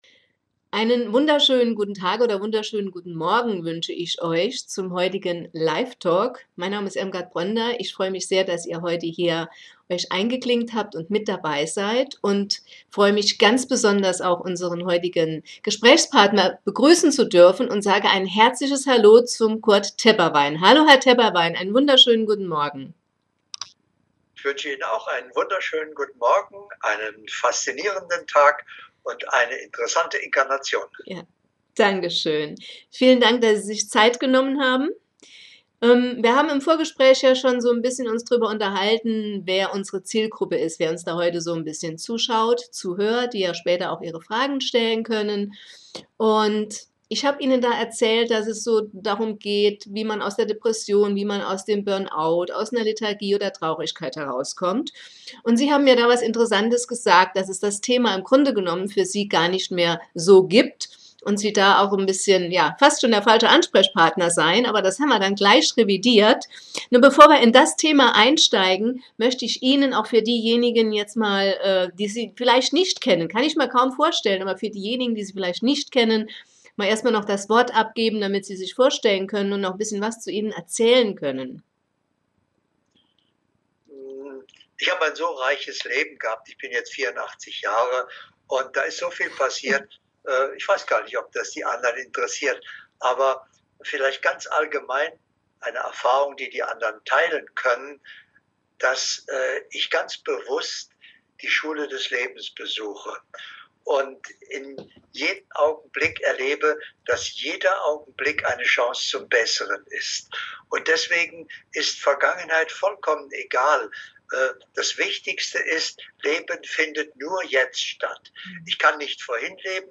Hier findest Du Interviews der Sprecher vom LebensFreudeKongress sowie im LiveTalk vom LebensFreudeRetreat